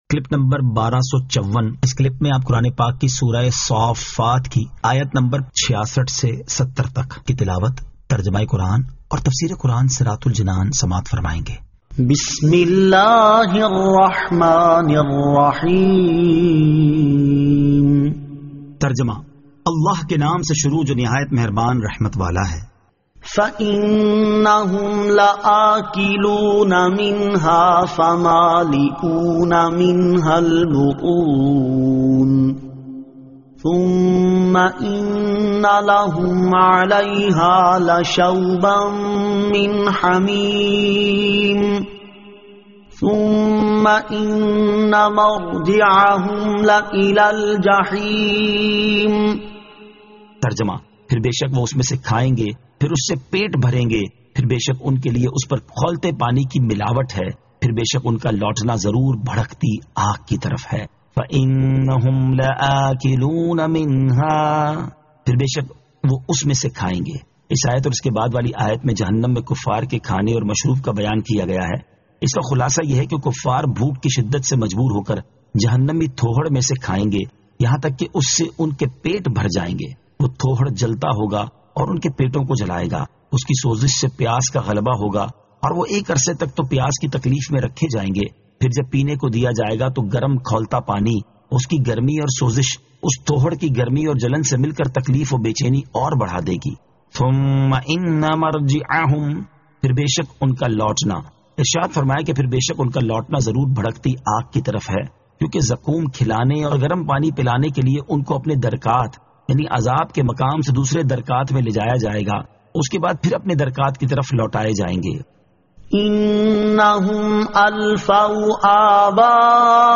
Surah As-Saaffat 66 To 70 Tilawat , Tarjama , Tafseer
2023 MP3 MP4 MP4 Share سُوَّرۃُ الصَّافَّات آیت 66 تا 70 تلاوت ، ترجمہ ، تفسیر ۔